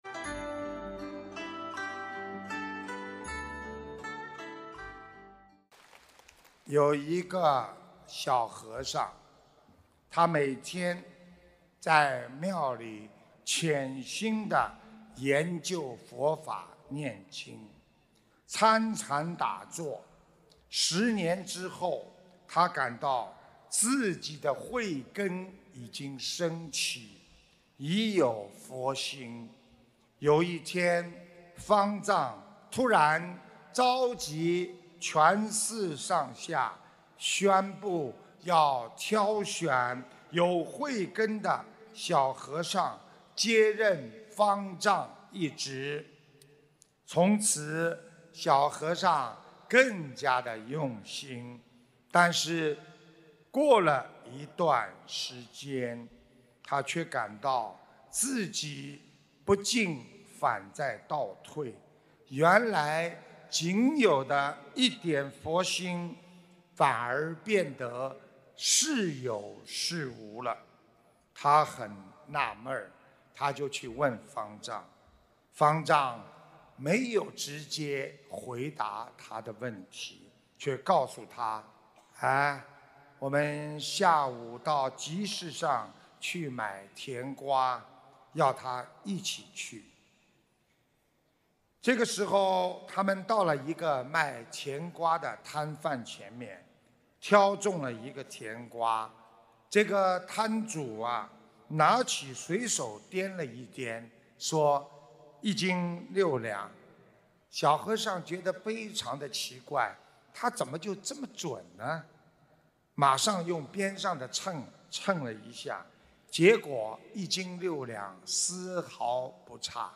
视频：38_香港《玄藝综述》现场解答会 开示 2016年7月3日 节选（二） - 法会节选 百花齐放
首页 >>弘法视频 >> 法会节选